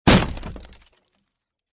Descarga de Sonidos mp3 Gratis: impacto golpe 1.